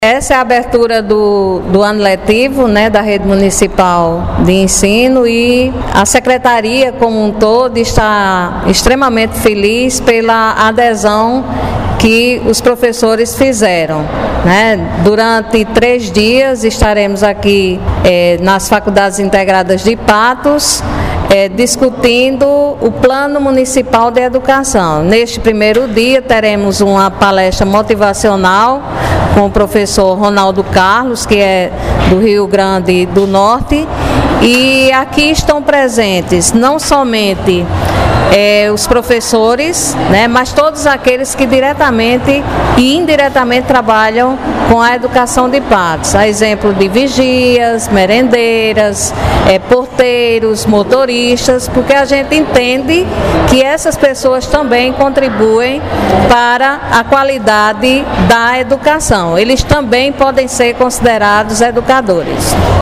Fala da secretária de Educação, Alana Candeia –